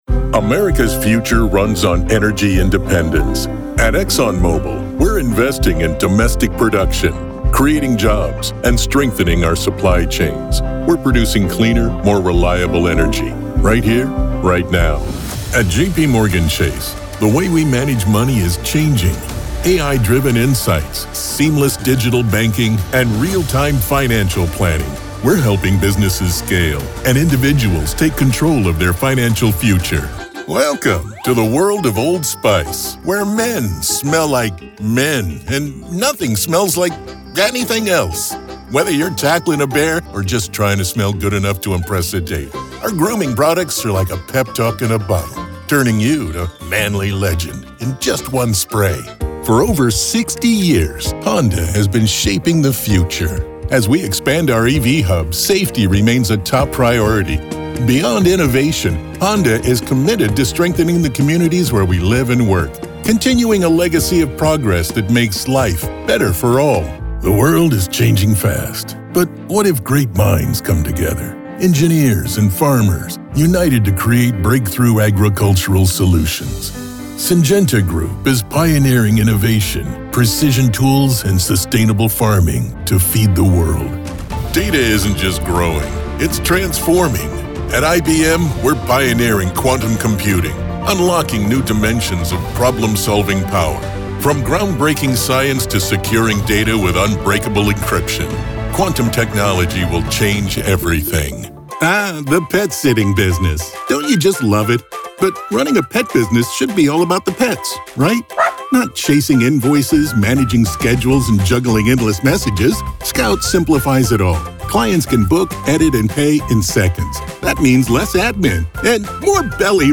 American/British Adult Male voice with gravitas
Corporate Narration
Middle Aged
Over 4 decades of using my voice to add that commanding, compelling, sympathetic, and friendly delivery.